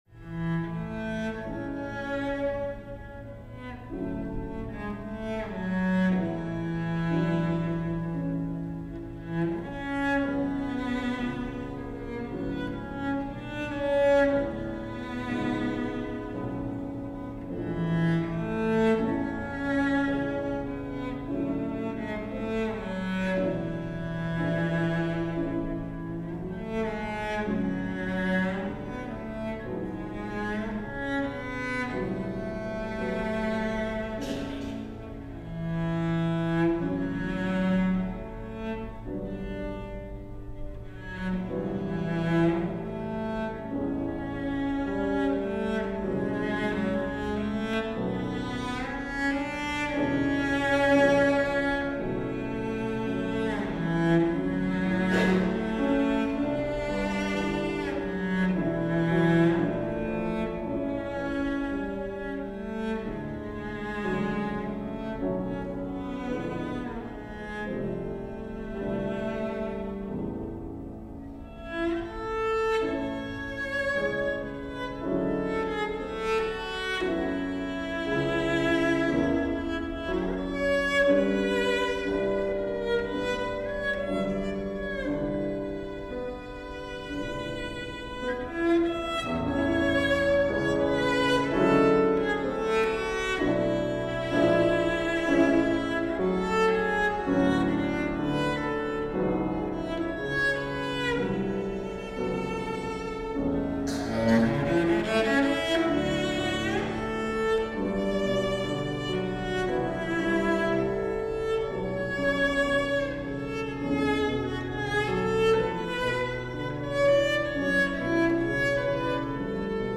cello & piano (or violin & piano)